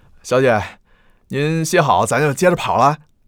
c01_9车夫_7.wav